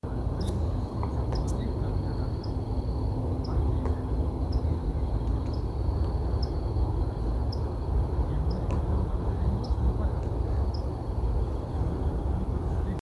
Bacurau-tesoura (Hydropsalis torquata)
Nome em Inglês: Scissor-tailed Nightjar
Detalhada localização: Parque San Carlos
Condição: Selvagem
Certeza: Observado, Gravado Vocal
Atajacaminos-tijera-1.mp3